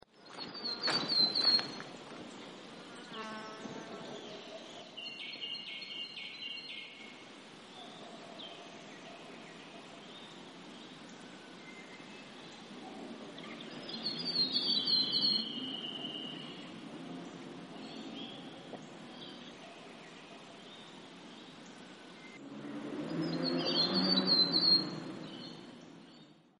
Scarlet Robin - Petroica multicolor
Voice: musical warble, ending in two long notes; quiet 'tick'.
Call 1: three warbling calls; a motorbike revs up on the last call.
Scarlet_Robin.mp3